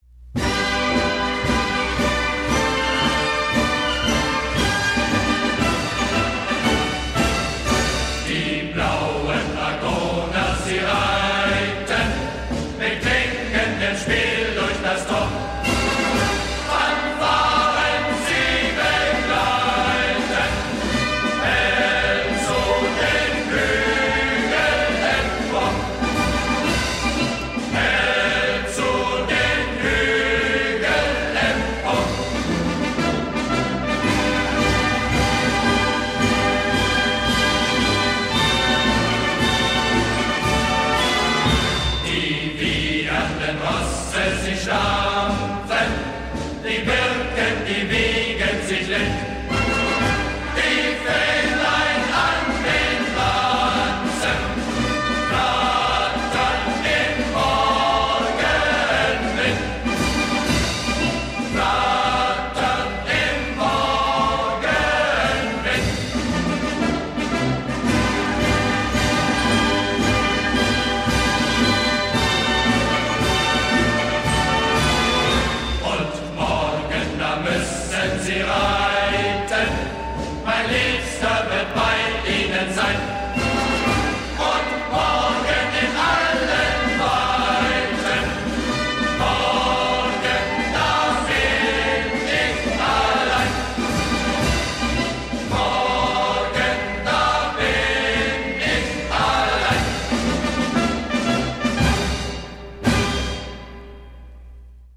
10 German Marching Songs